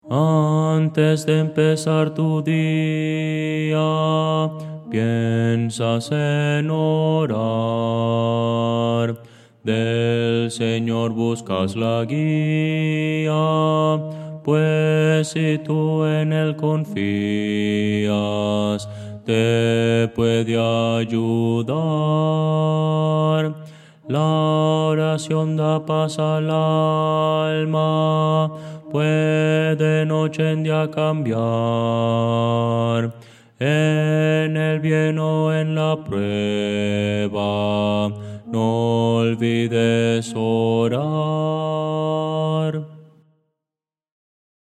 Voces para coro
Bajo – Descargar